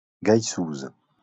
Geishouse (French pronunciation: [ɡaisuz]